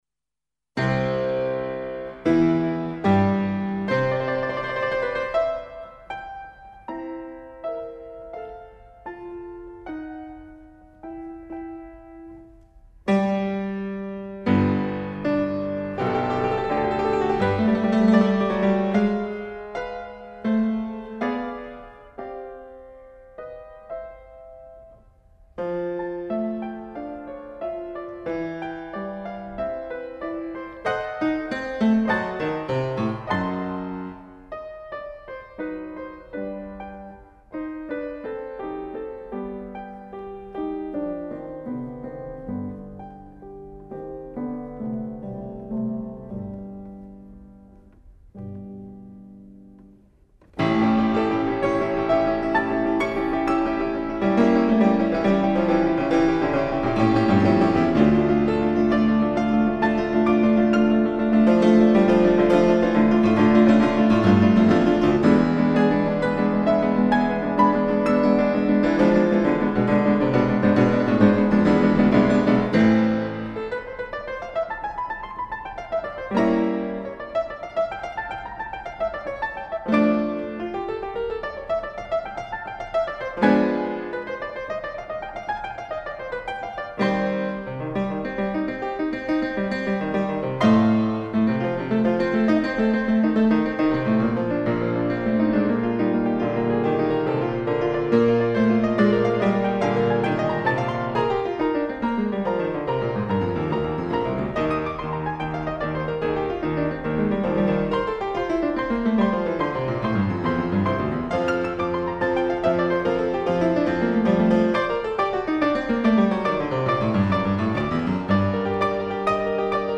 Es la versión de Paul Badura-Skoda en un fortepiano Schantz que sale en la portada del CD: